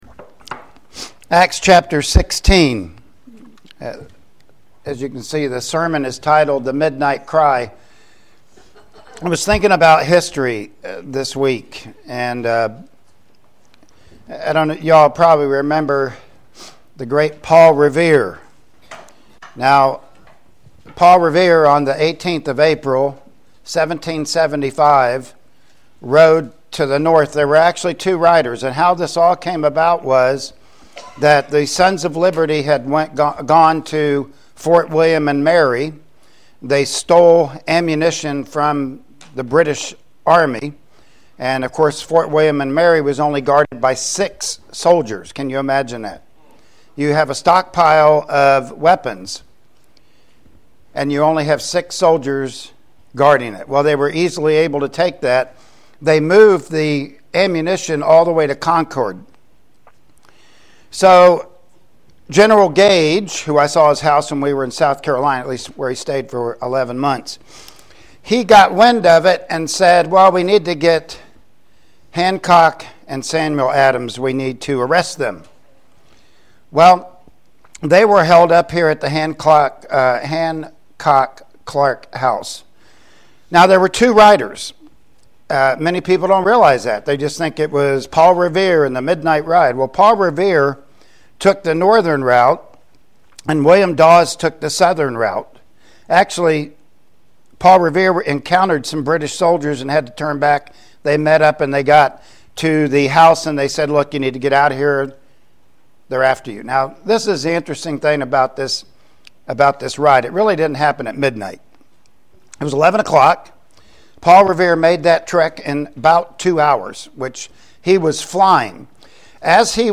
Passage: Acts 16:25-28 Service Type: Sunday Morning Worship Service Topics